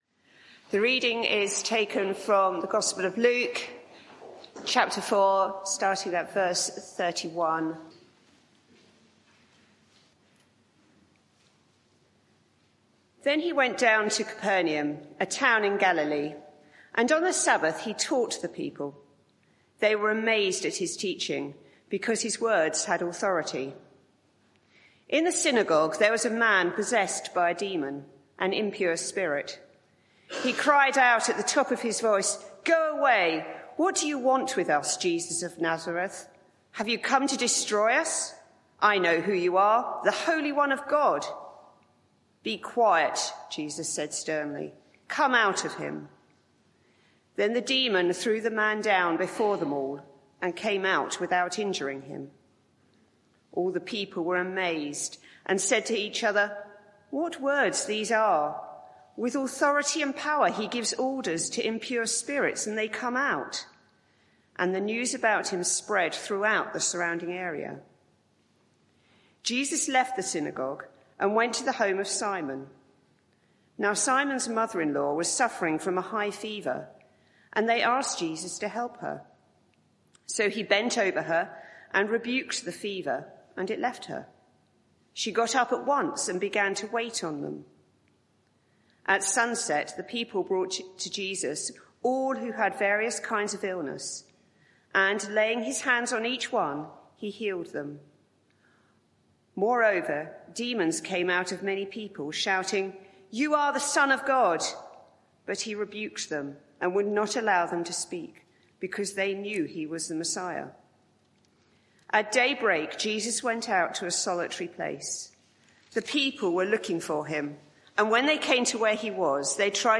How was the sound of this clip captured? Media for 11am Service on Sun 12th Jan 2025 11:00 Speaker